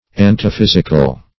Search Result for " antiphysical" : The Collaborative International Dictionary of English v.0.48: Antiphysical \An`ti*phys"ic*al\, a. [Pref. anti- + physical.] Contrary to nature; unnatural.
antiphysical.mp3